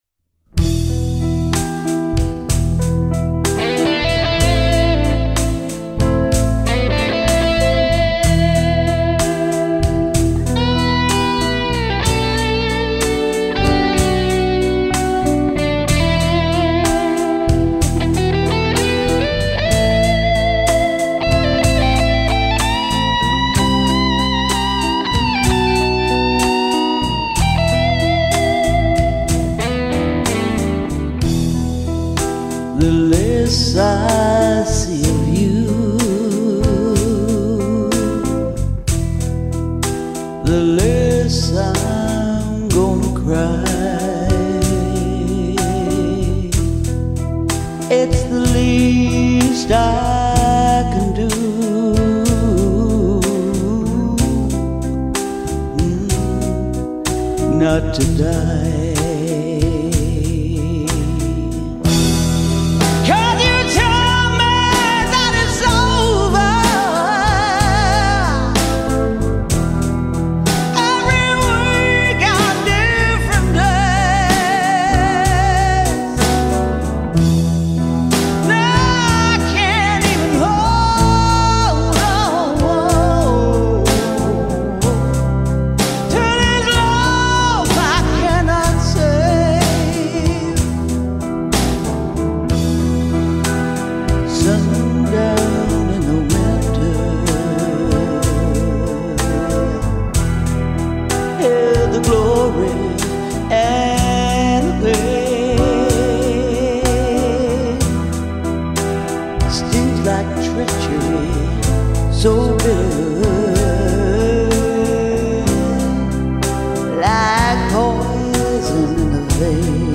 Blues Jazz